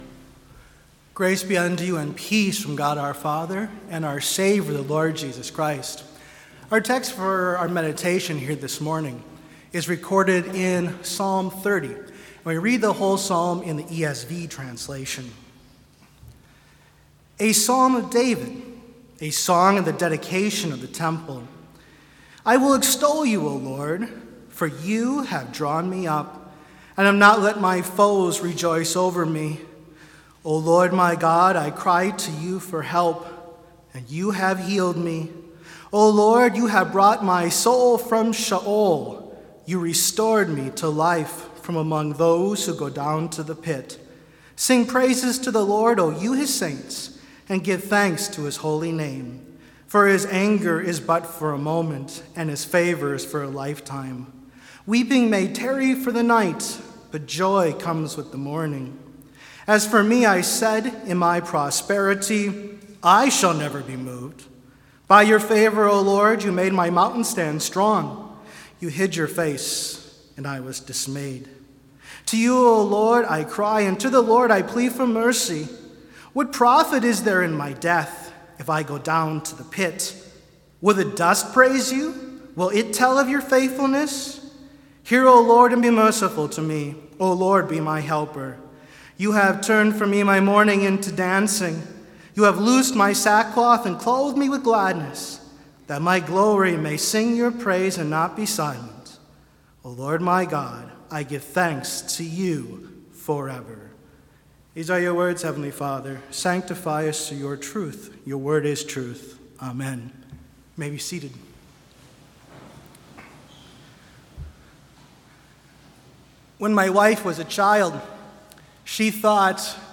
Complete service audio for Chapel - September 20, 2021